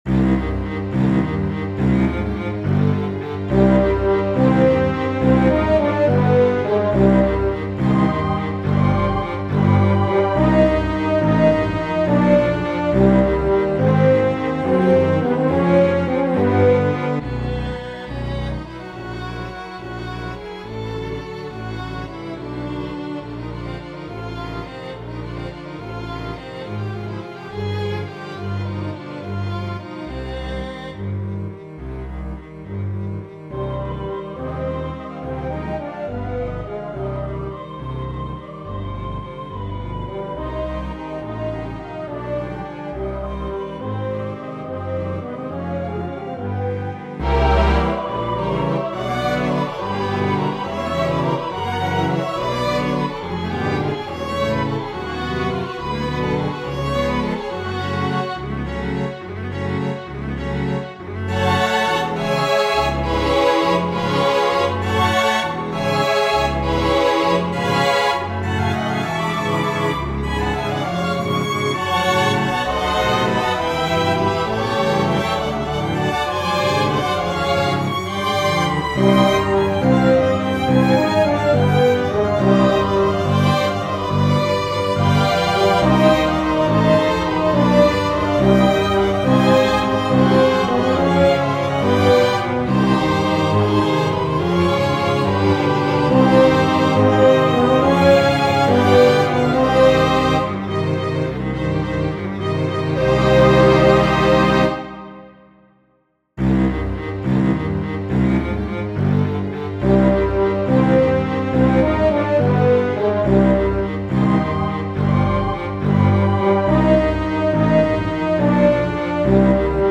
It does repeat; 1 minute 45 seconds is the length of the written music prior to repeat.